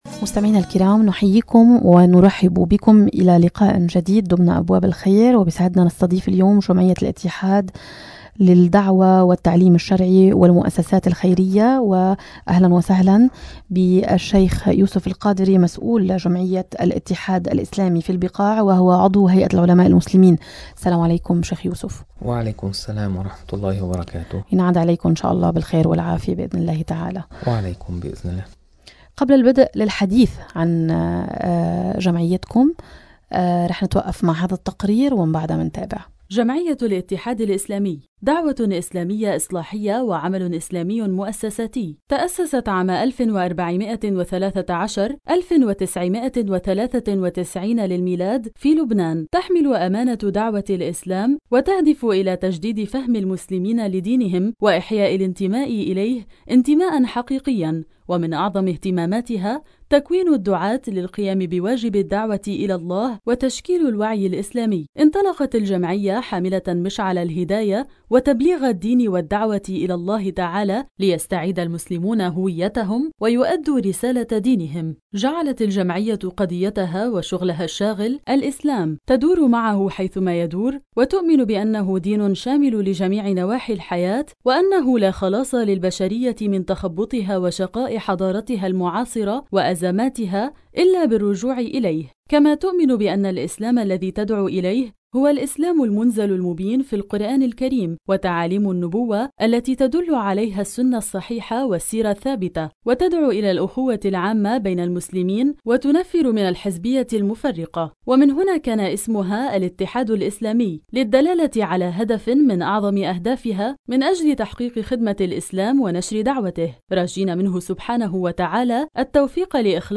أنشطة جميعة الاتحاد الإسلامي وحملة “رمضان إشراقة روح” | مقابلة